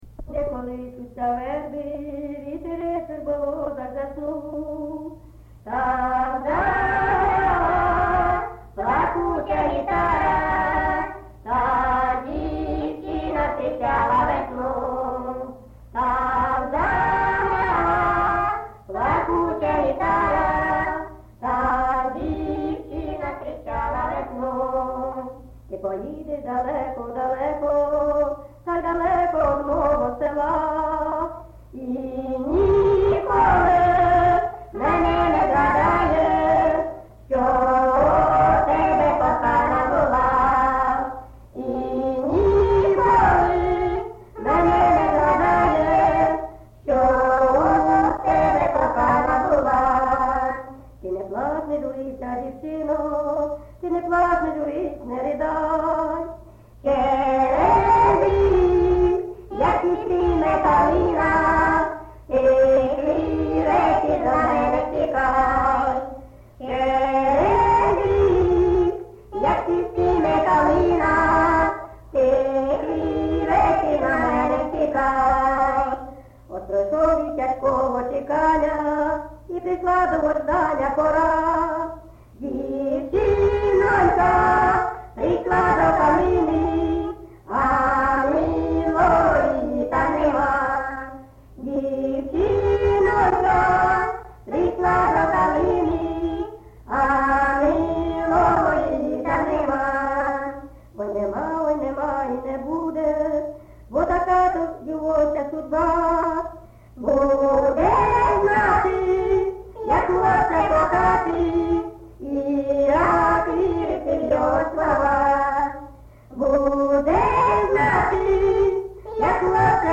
ЖанрПісні з особистого та родинного життя, Пісні літературного походження
Місце записус. Золотарівка, Сіверськодонецький район, Луганська обл., Україна, Слобожанщина